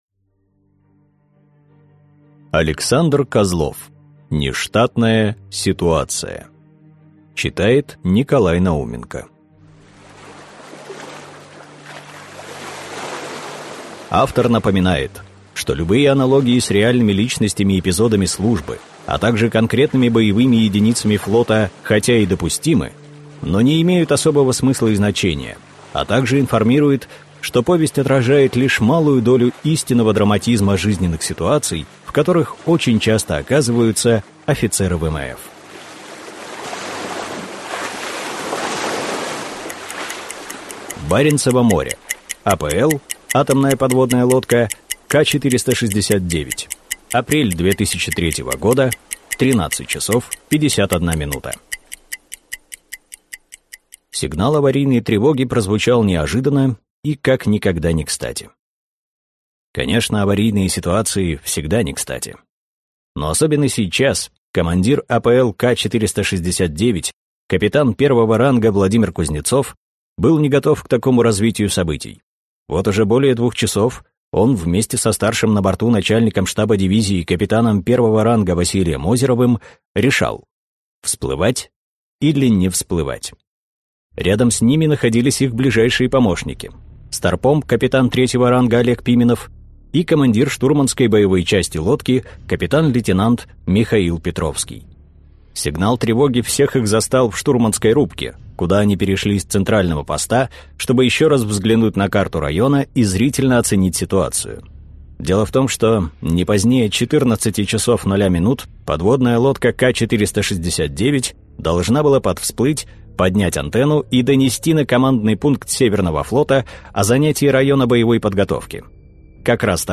Аудиокнига Нештатная ситуация | Библиотека аудиокниг
Прослушать и бесплатно скачать фрагмент аудиокниги